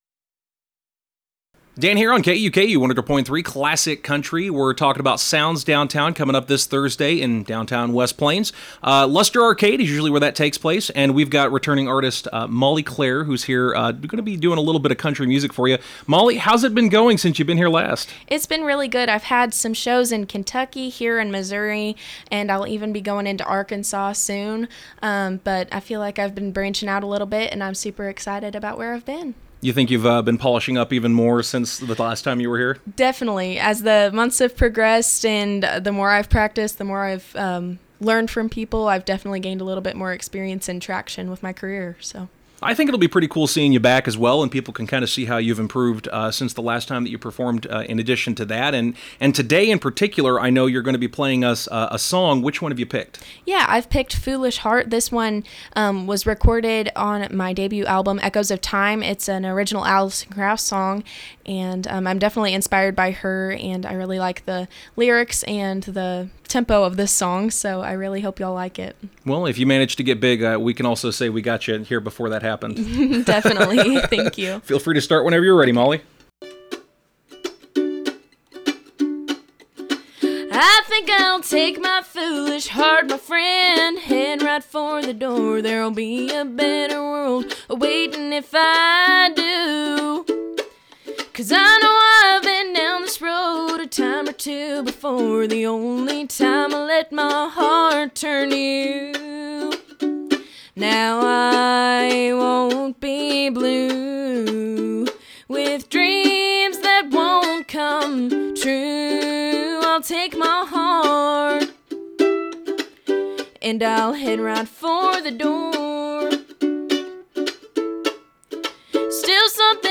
While in the studio